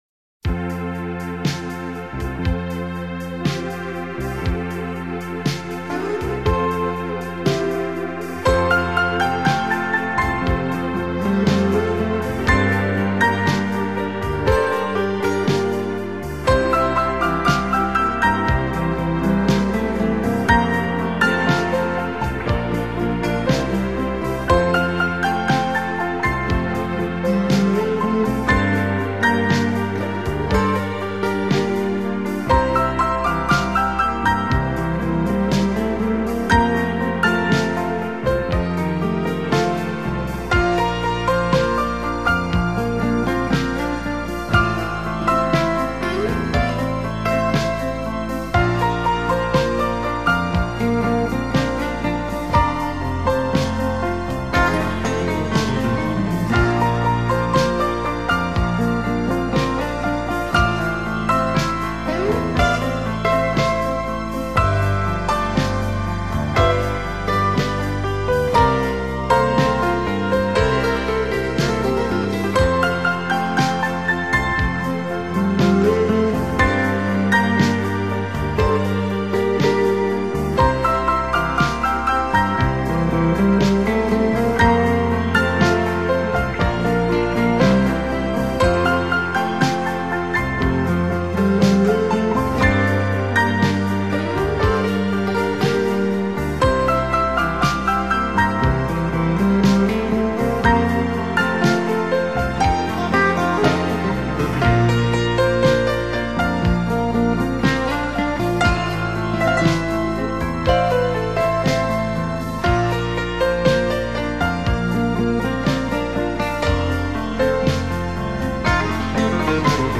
来自瑞士一尘不染清新的天籁音乐
减压音乐特别根据人类生理和心理活动规律而创，浪漫温馨的音乐元素融合各种